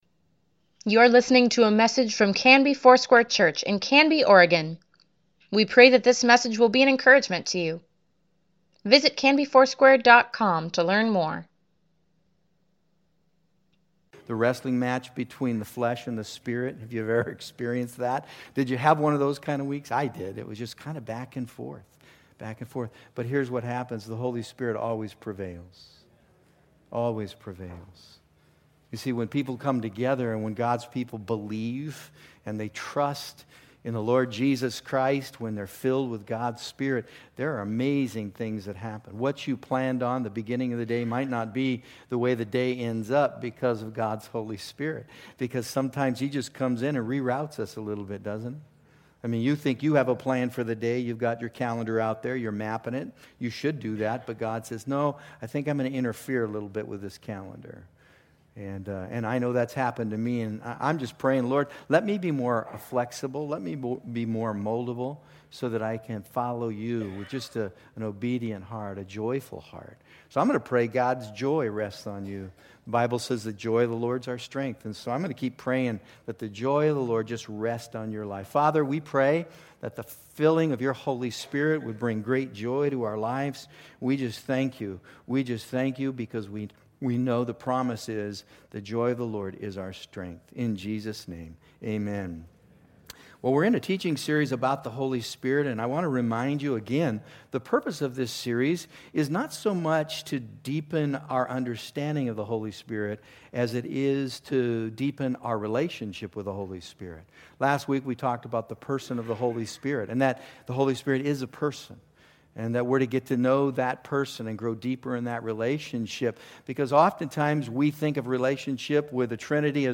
Weekly Email Water Baptism Prayer Events Sermons Give Care for Carus The Promise of the Holy Spirit June 9, 2019